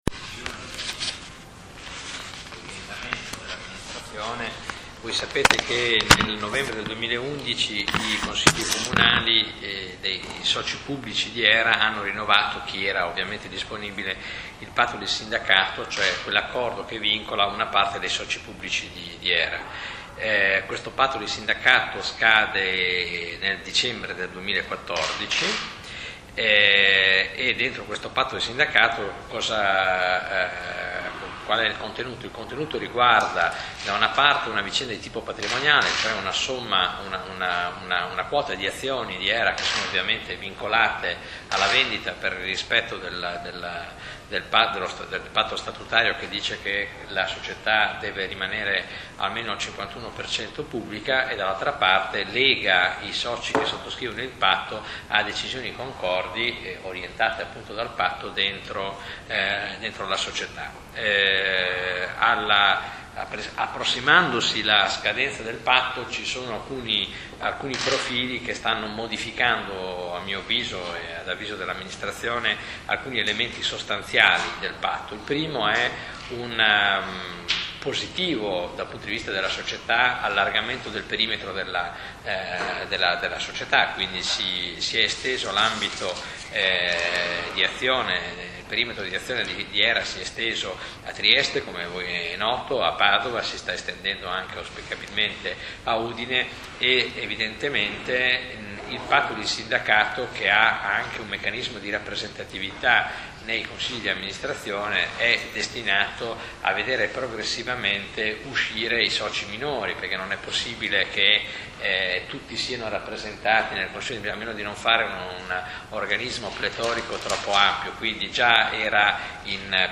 Conferenze stampa
CONFERENZA STAMPA - Venerdì 17 gennaio alle 11.30 nella sala di Giunta della residenza municipale
Questo l'audio della conferenza stampa odierna (17gen2014) tenuta dal sindaco Tiziano Tagliani relativa alla volontà, condivisa con i componenti della Giunta, di non rinnovare la sottoscrizione del "Patto di Sindacato" con Hera, in scadenza nel dicembre 2014.